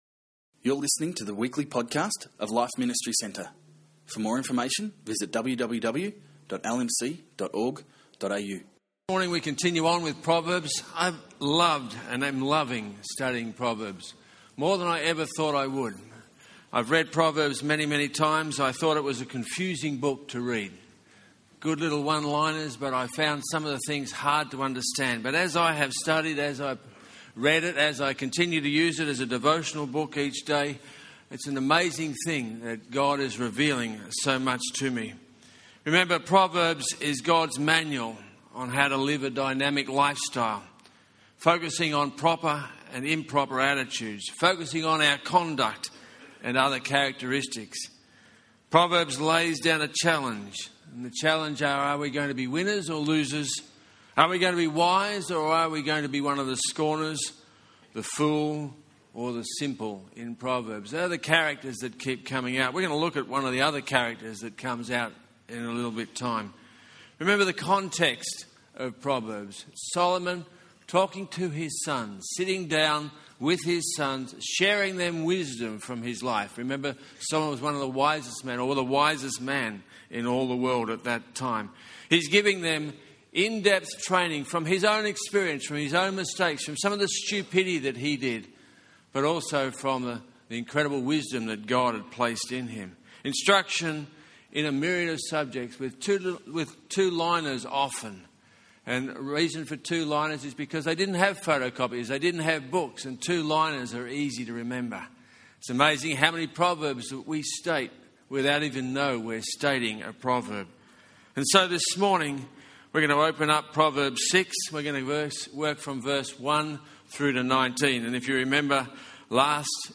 In this talk, he looked at 1. How we handle money/business 2. How we discipline ourselves and 3. How we build community.